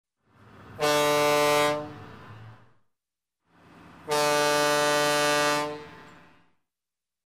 Звуки корабля, теплохода